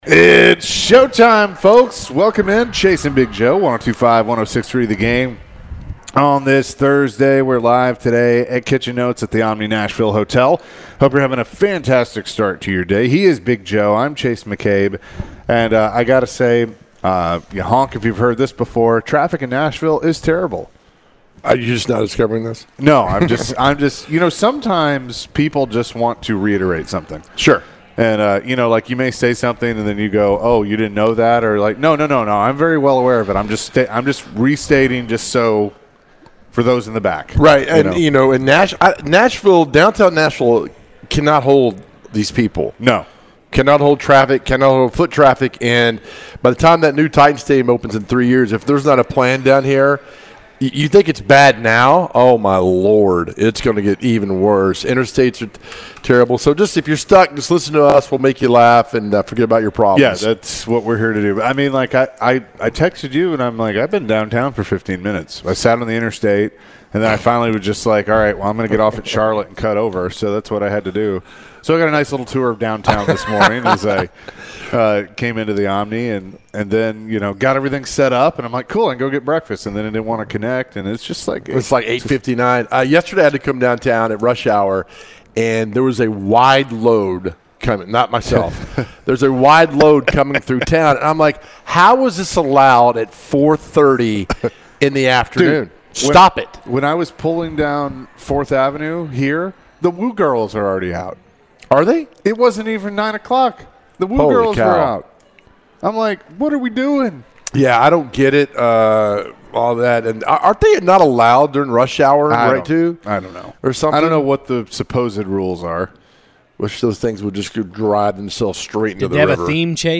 What do you miss most in your life? Later in the hour the guys answered some calls and texts around the question of the day.